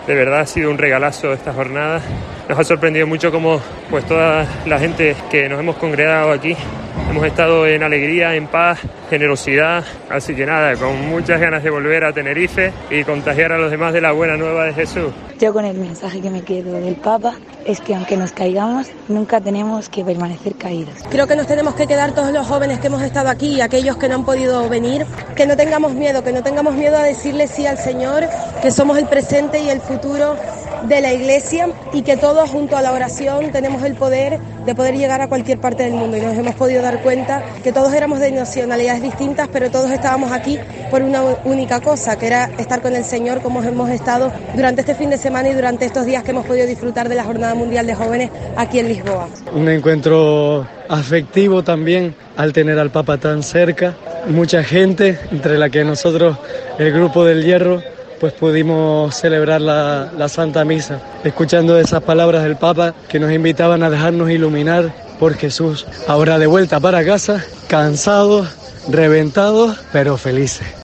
Vivencias y testimonios de los canarios asistentes a la JMJ de Lisboa 2023
Nada más terminar la misa, con la emoción aún en sus voces, e incluso con una clara afonía, pudieron sintetizar lo que más les llegó de la experiencia que estaban a punto de concluir.